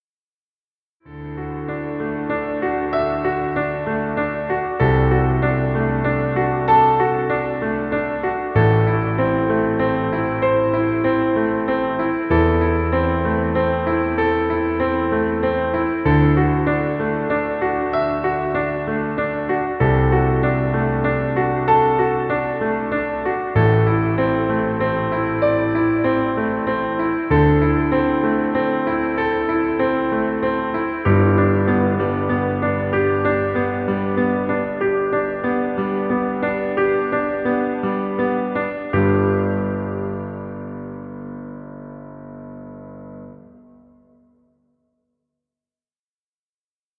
Cinematic Romantic music.